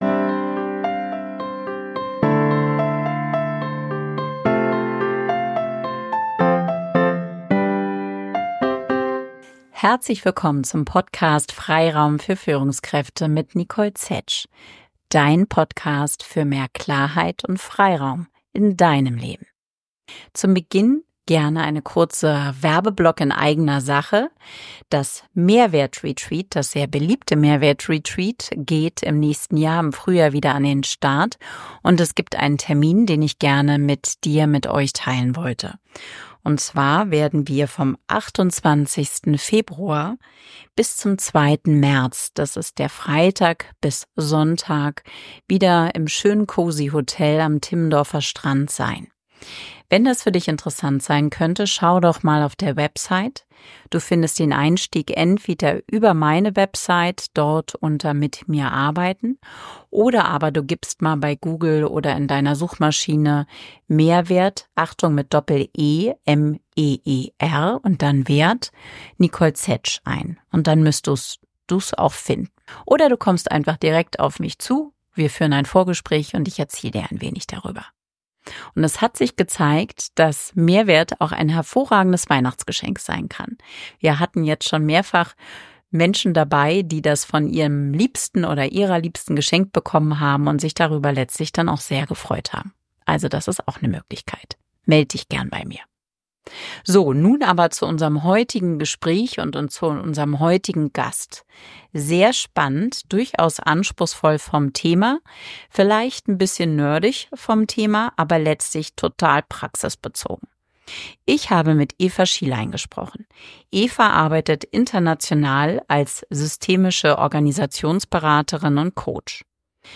Unser Interview ersetzt natürlich nicht die Lektüre des Buchs. Unser Gespräch soll Interesse wecken für das Buch und einen ersten Einblick in das Thema geben.